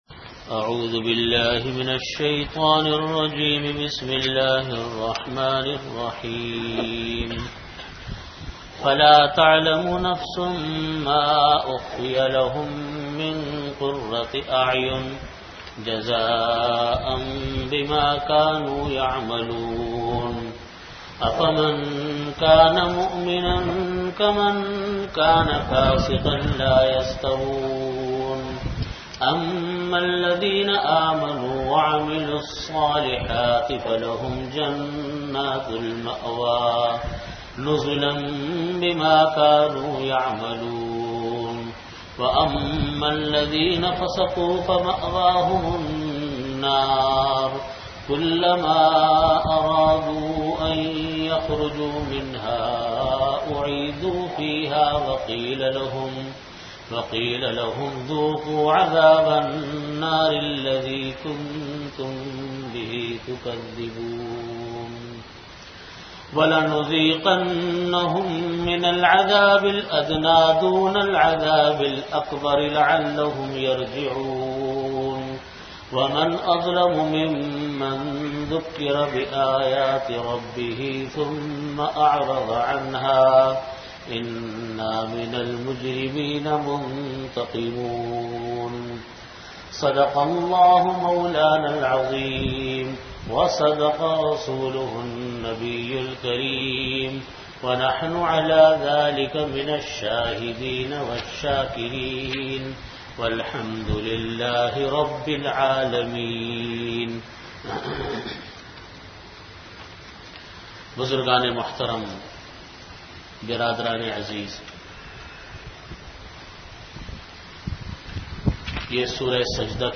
Tafseer · Jamia Masjid Bait-ul-Mukkaram, Karachi